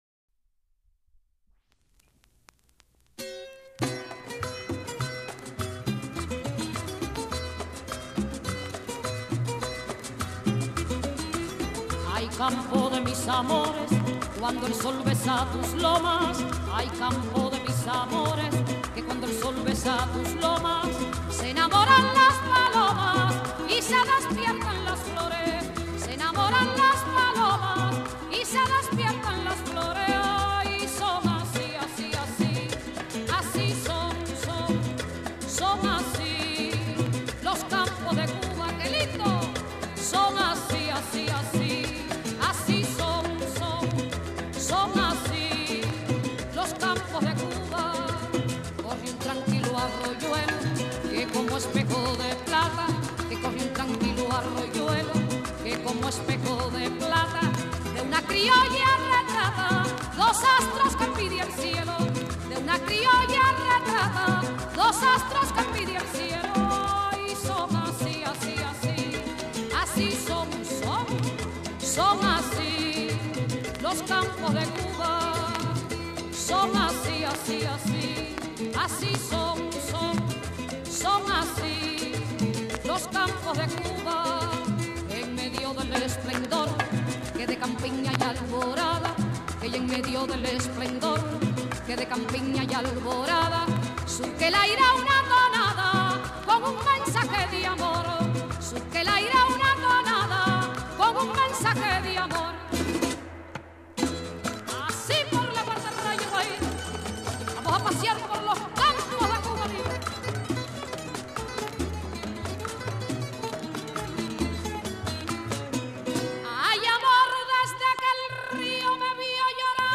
キューバのCAMPESINO（農民の唄）を集めたコンピレーション
Son , ソン , Guajira , ワヒーラ中心の内容